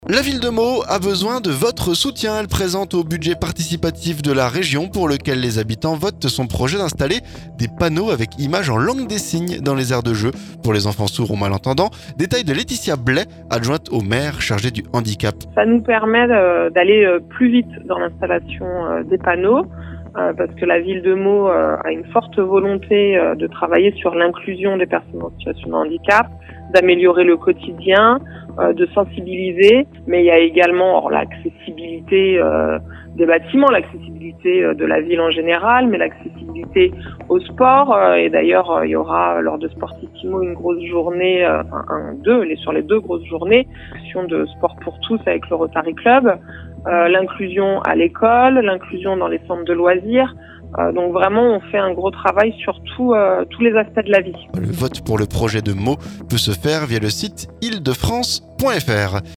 Elle présente au budget participatif de la région, pour lequel les habitants votent, son projet d'installer des panneaux avec images en langue des signes dans les aires de jeux, pour les enfants sourds ou malentendants. Détails de Laëtitia Blay, adjointe au maire chargée du handicap.